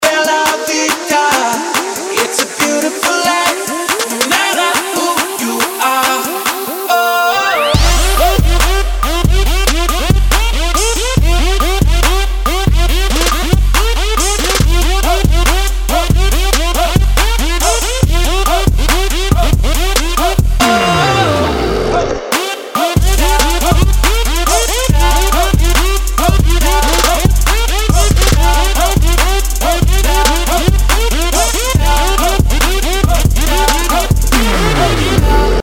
• Качество: 192, Stereo
Отличная Trap-Версия Известной композиции